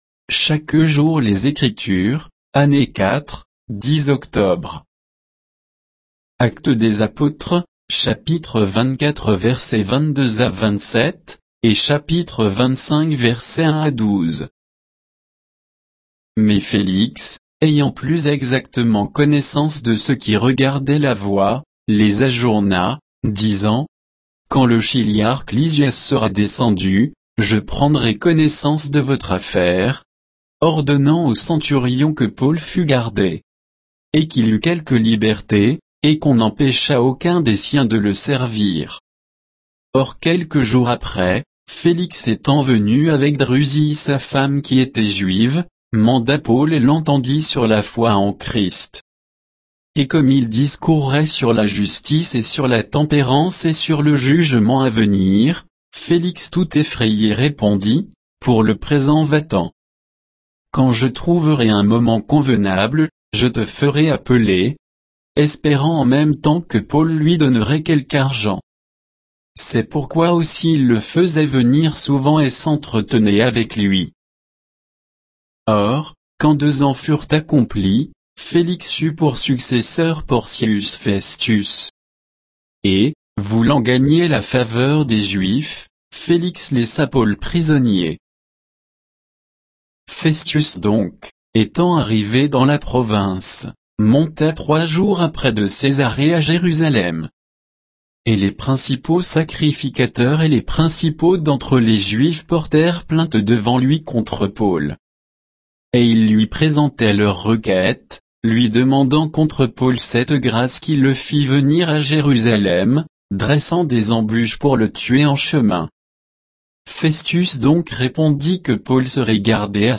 Méditation quoditienne de Chaque jour les Écritures sur Actes 24